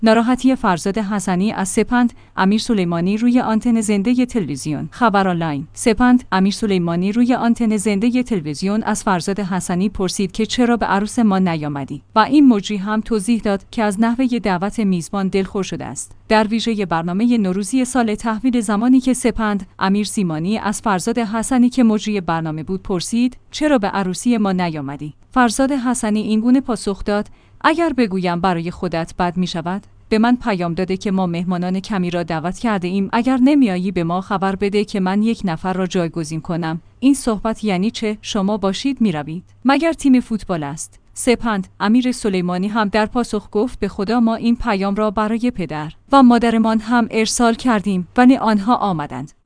ناراحتی فرزاد حسنی از سپند امیرسلیمانی روی آنتن زنده تلویزیون